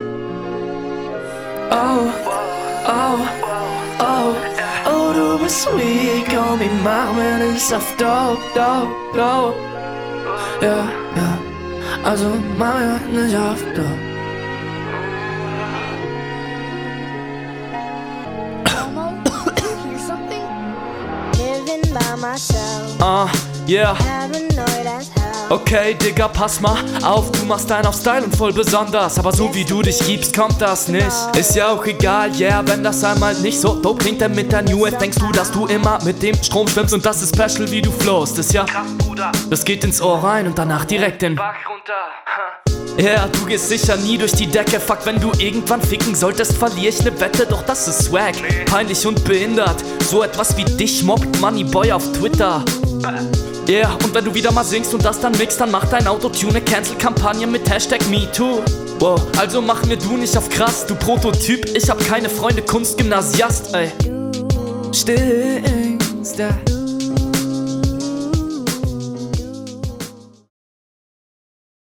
Cooler Beat Flow ist mir für diesen Beat fast schon n bissl zu abwechslungsreich.